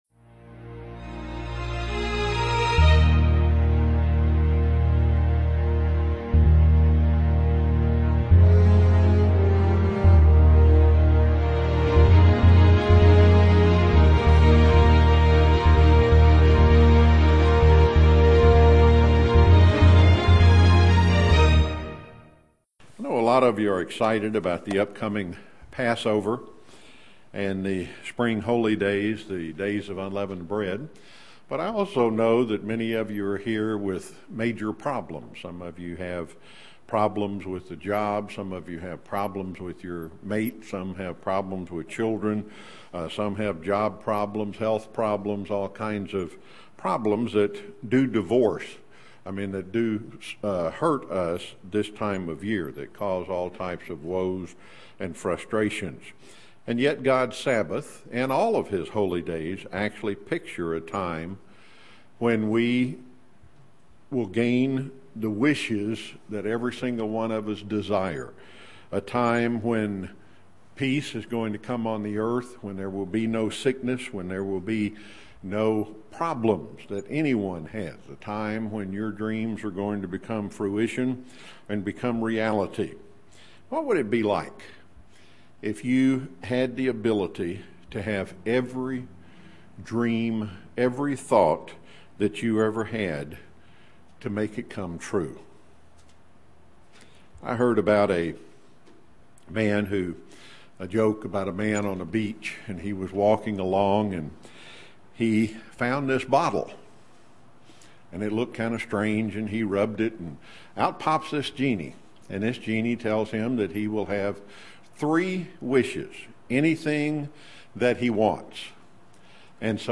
UCG Sermon Studying the bible?
Given in Chattanooga, TN